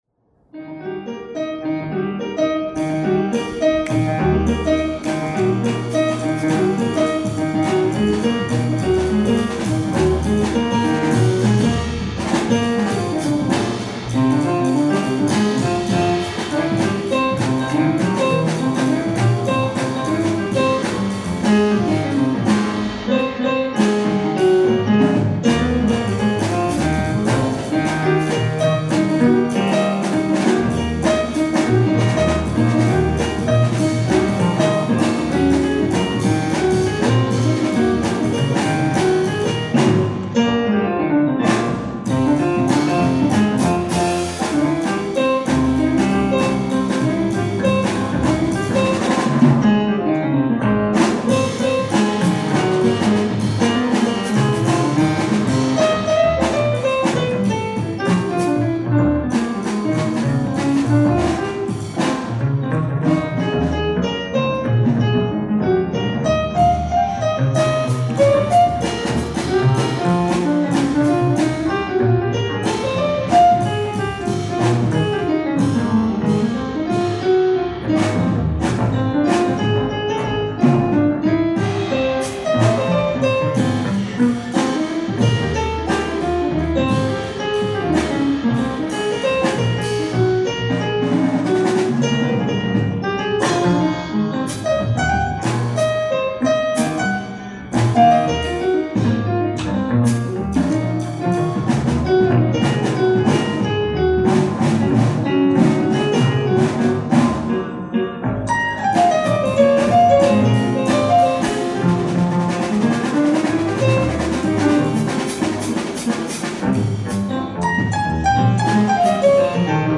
ライブ・アット・セジョン・センター、ソウル 05/19/2013
※試聴用に実際より音質を落としています。